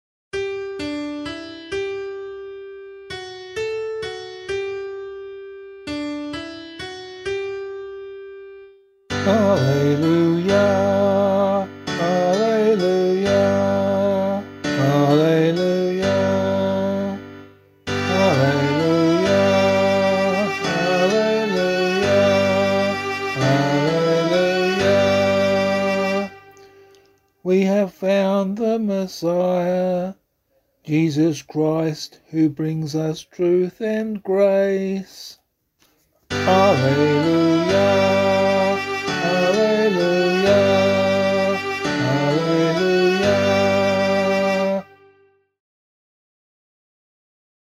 036 Ordinary Time 2 Gospel B [LiturgyShare F - Oz] - vocal.mp3